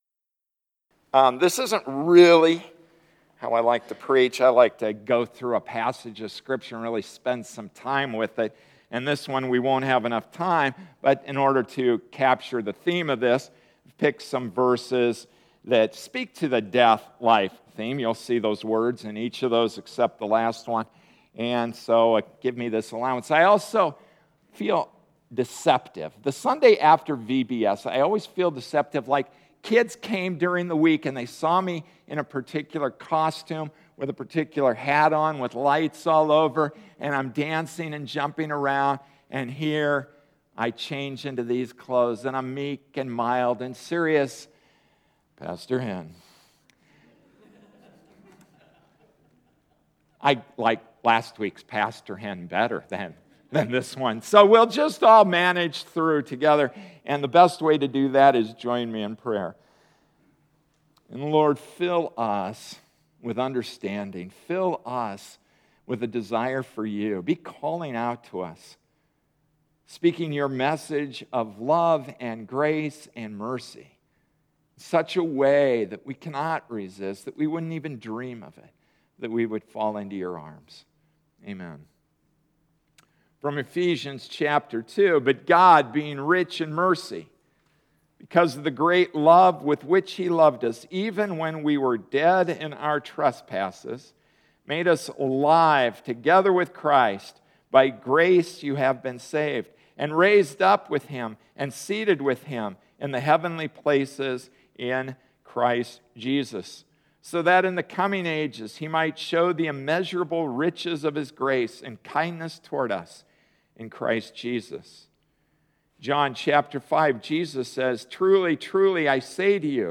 August 10, 2014 From Death to Life Passage: Ephesians 2:4-7; John 5:24; Romans 6:4-8; Romans 8:10-11; Deuteronomy 30:19-20 Service Type: Sunday Morning Service “From Death to Life” Introduction: What does death look like?